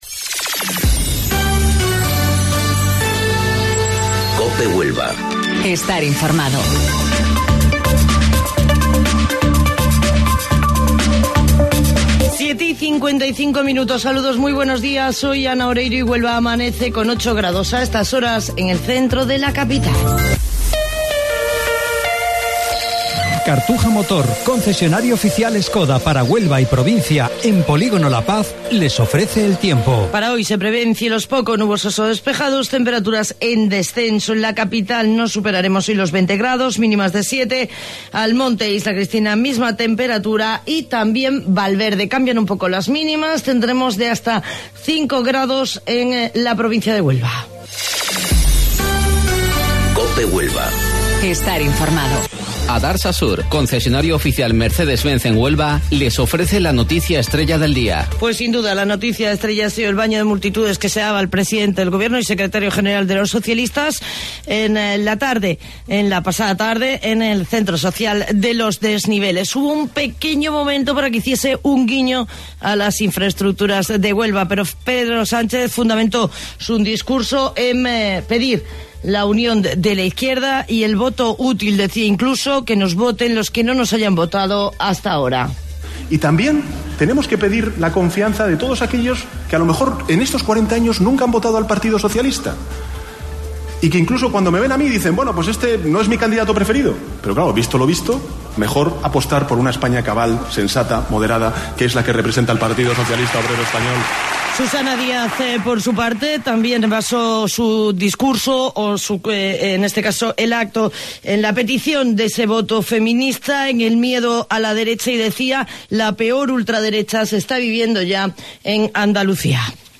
AUDIO: Informativo Local 07:55 del 4 de Abril